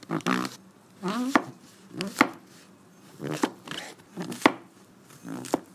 烹饪 " 斯诺伊登香槟酒 1
描述：在合成砧板上慢慢切蘑菇。用iPhone 6录制。
Tag: 厨房 厨师 蘑菇 烹饪 蔬菜